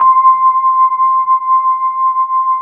FEND1L  C5-L.wav